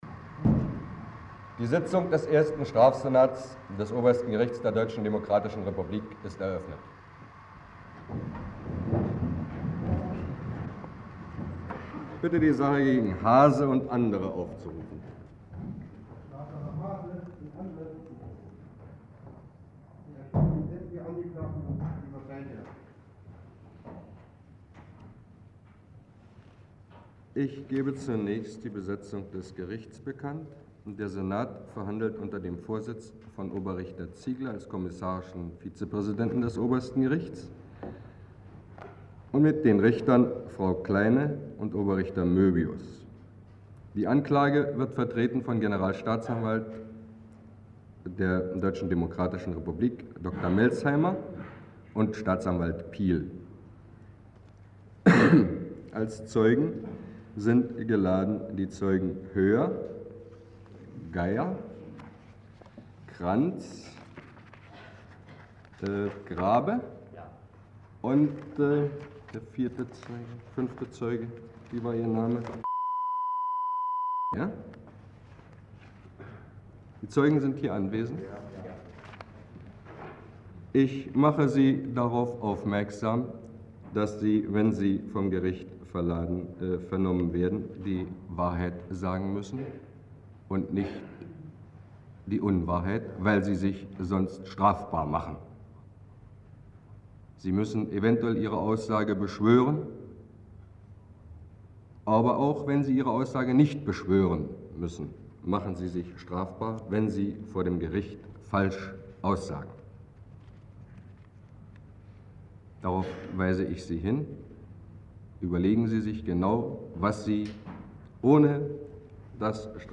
Vom 18. bis 21. Dezember 1953 fand vor dem Obersten Gericht der DDR ein auch als "Gehlen-Prozess" bekannter Schauprozess wegen Spionage im Auftrag der Organisation Gehlen statt.
Die Prozessaufnahme ist als Tonband in der Hauptabteilung IX des MfS ( HA IX ) im Archiv des BStU überliefert.